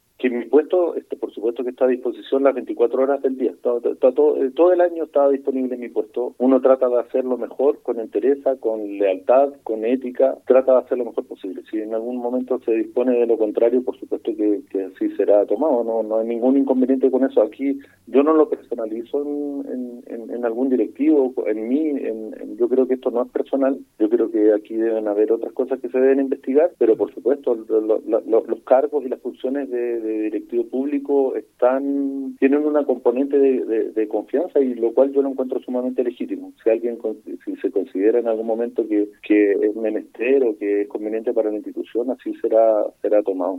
En entrevista con Radio Bío Bío en la ciudad, la autoridad sanitaria fue consultada por el ánimo que hay entre los trabajadores tras lo expuesto, replicando que hay “una consternación de toda la comunidad hospitalaria, esto nos ha pegado muy fuerte“.